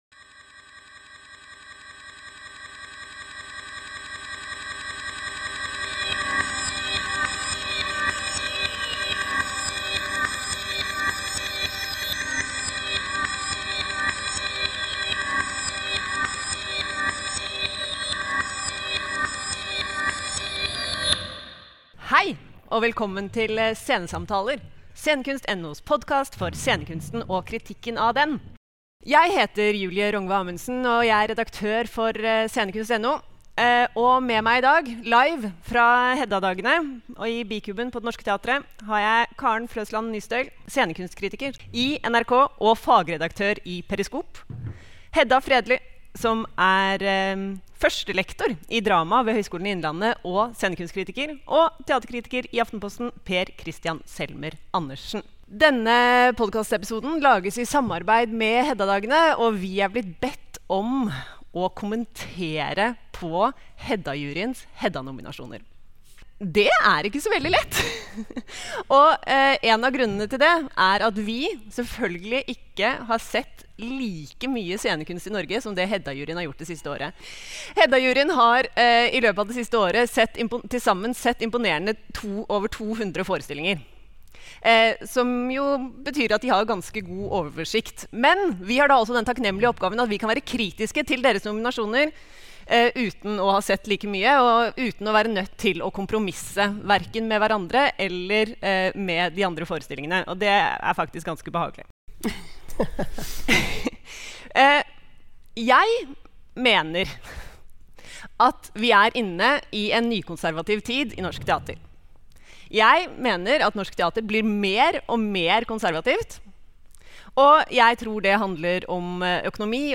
Scenesamtaler 35: Live fra Heddadagene